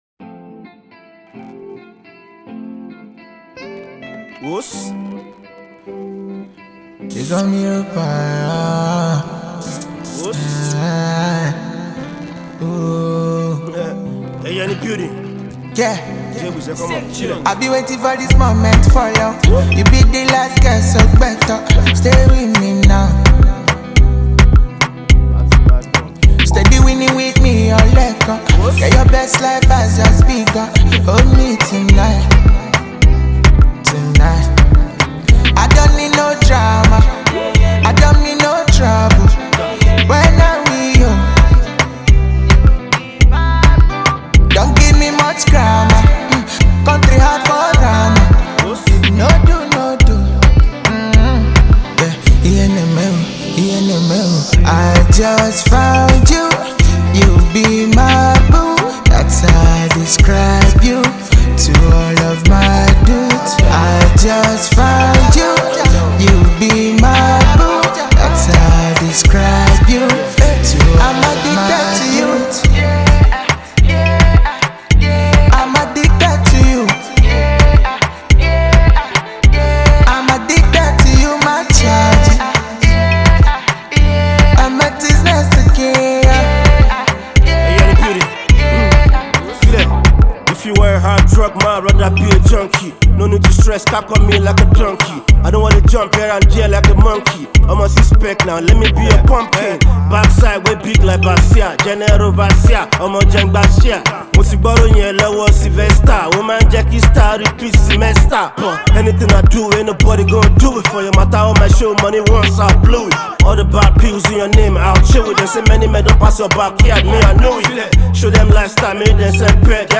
infectious single
The love song